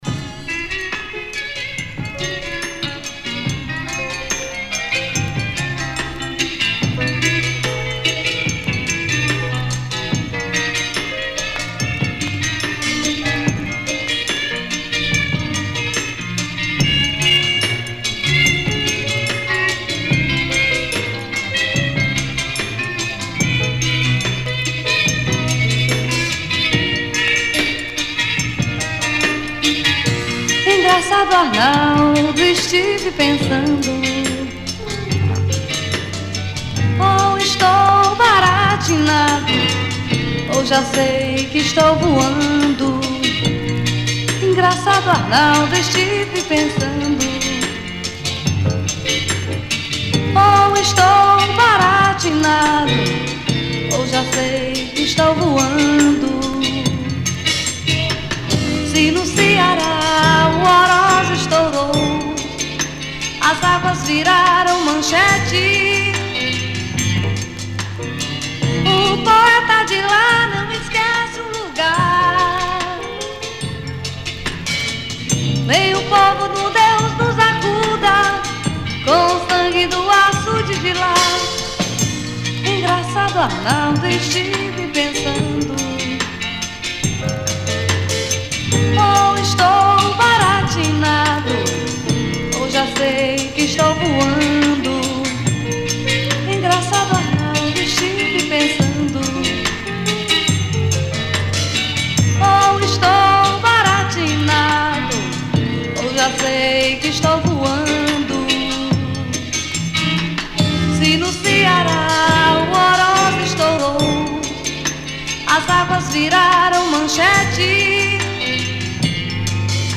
120   03:05:00   Faixa:     Rock Nacional